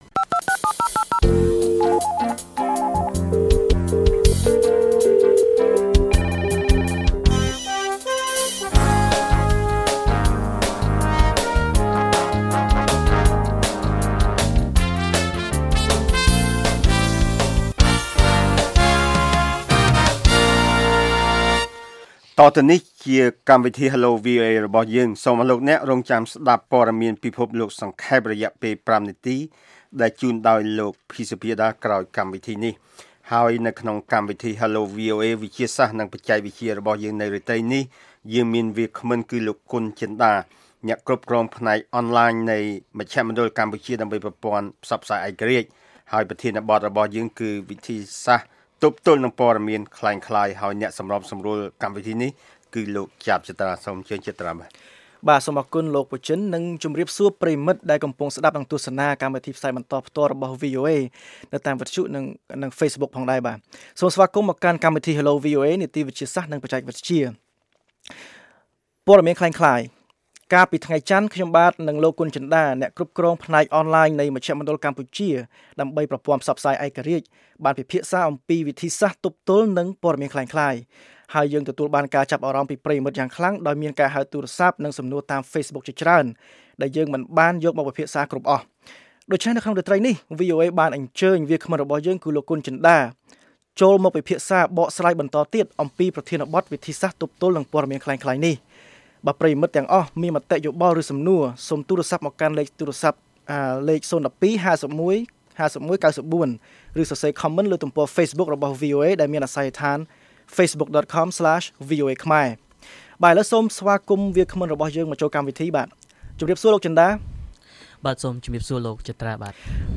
បានចូលរួមជាវាគ្មិនក្នុងកម្មវិធី Hello VOA